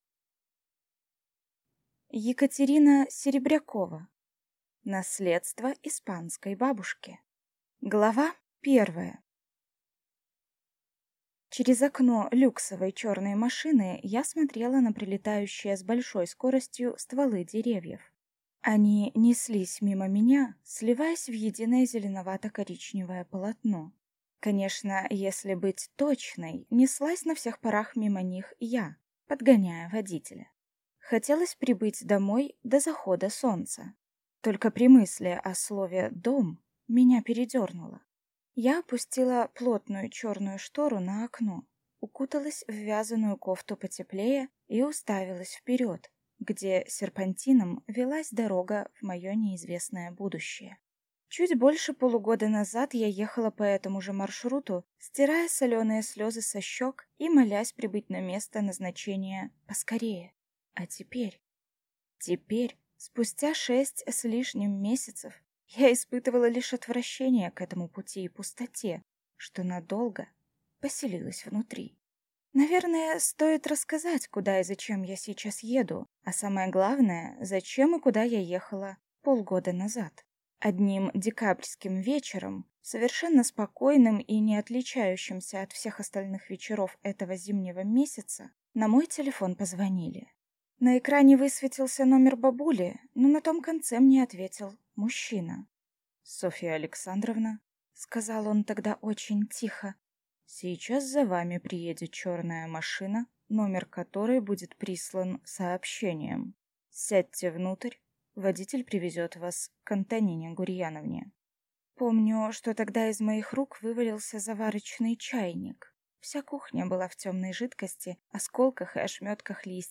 Aудиокнига Наследство испанской бабушки